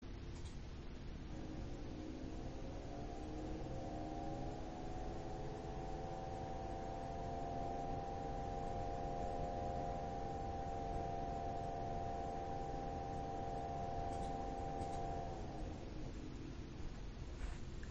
Ich habe anbei ein paar Aufnahmen gemacht (ca. 20cm Abstand):
• 3x jeden Fan einzeln mit ~1300rpm / 39%.
Lüfter 1 und Lüfter 3 sind problematisch.
fan3 1300rpm.mp3